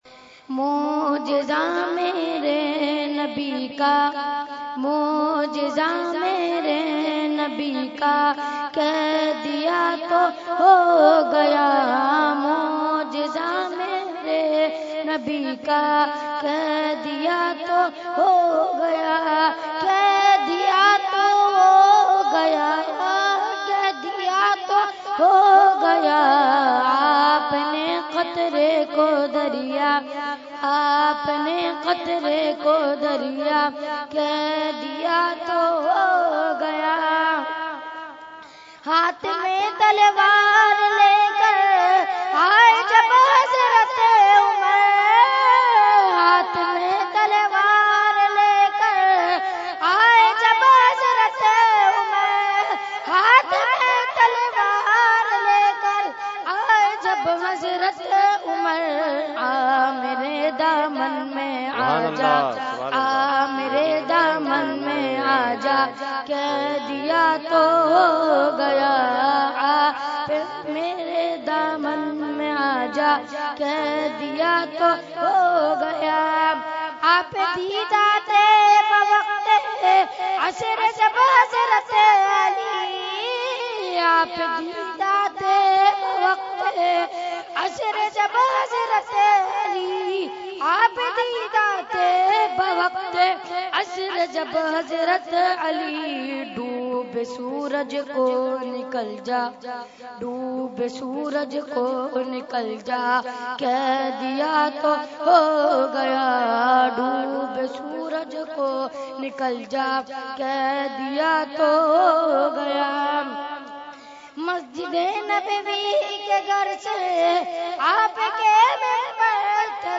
Category : Naat | Language : UrduEvent : Urs Makhdoome Samnani 2017